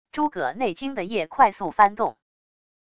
诸葛内经的页快速翻动wav音频